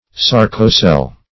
Search Result for " sarcocele" : The Collaborative International Dictionary of English v.0.48: Sarcocele \Sar"co*cele\, n. [Gr.
sarcocele.mp3